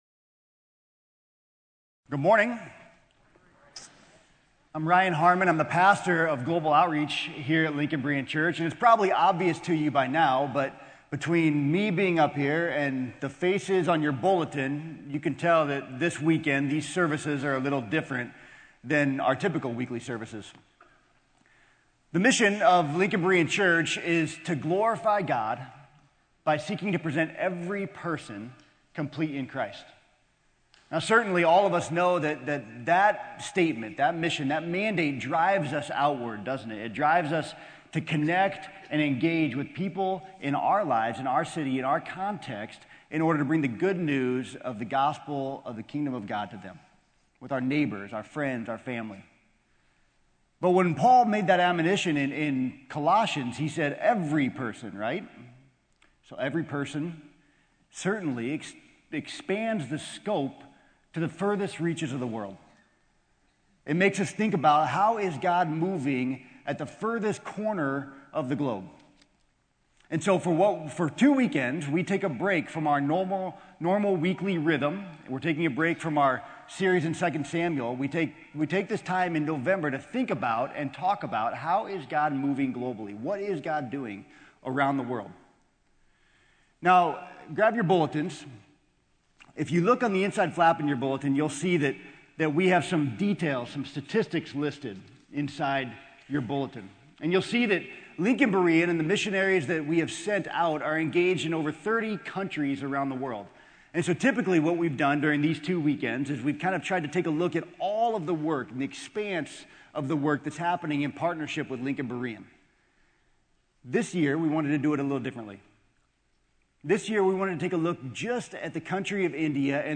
Sermon: Global Outreach Week 1